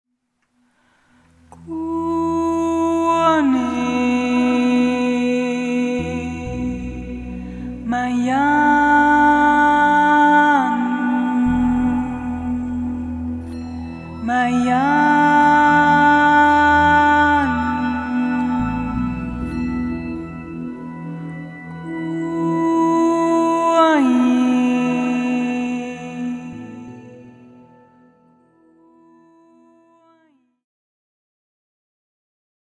Meditative / Poesie
Momentum-Aufnahmen